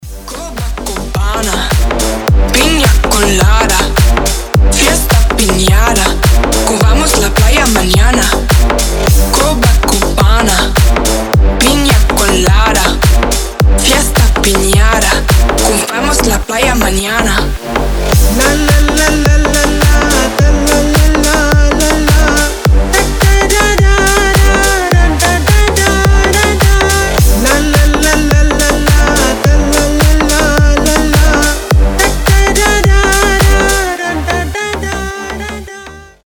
• Качество: 320, Stereo
deep house
зажигательные
мощные басы
Brazilian bass
Клубняк летних тусовок